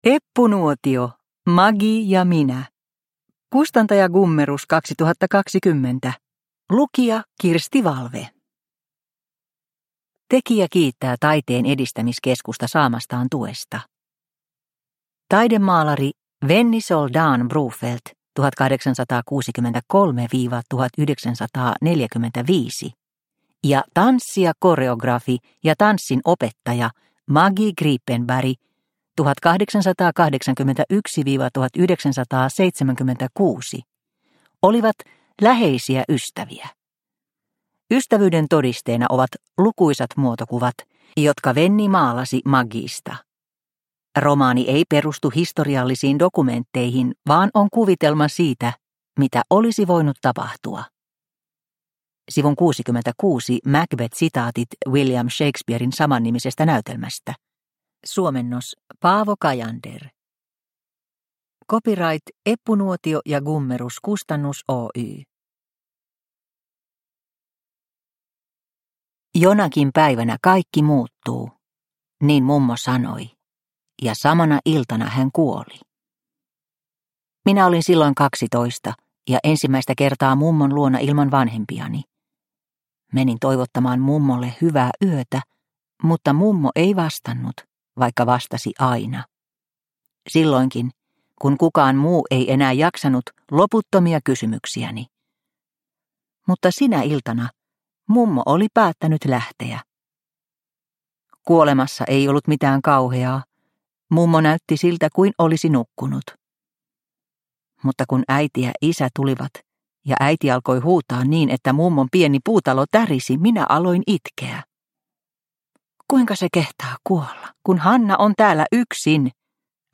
Maggie ja minä – Ljudbok – Laddas ner